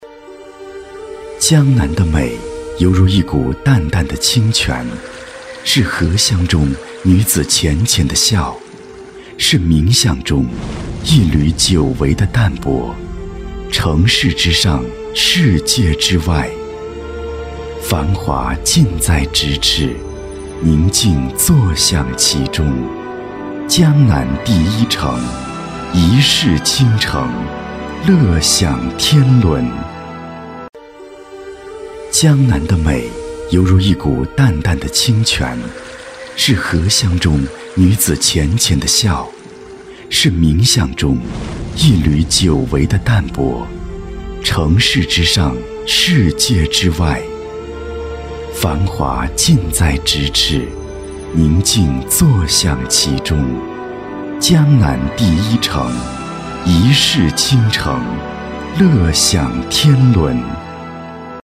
• 男S39 国语 男声 宣传片-江南地产-房产行业宣传片-沉稳大气 大气浑厚磁性|积极向上